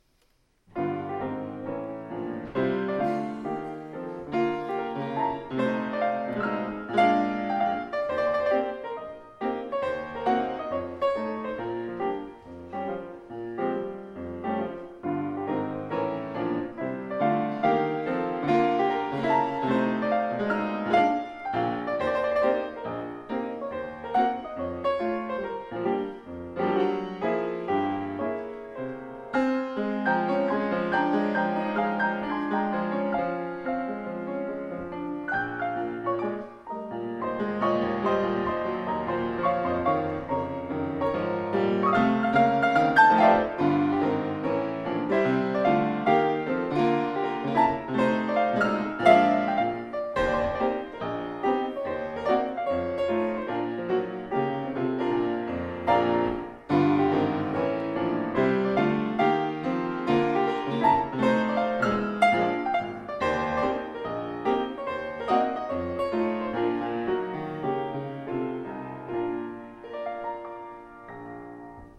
[Fuusm-l] Some music from past services